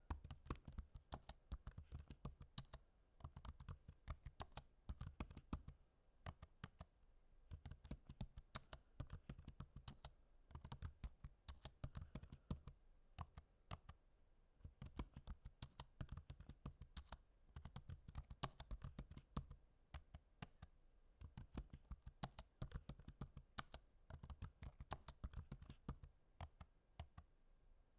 typewriting_fast
computer keys typewriting typing writing sound effect free sound royalty free Memes